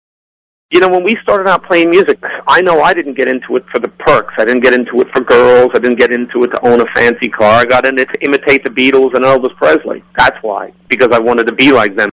*Audio clip from August 18, 2007 interview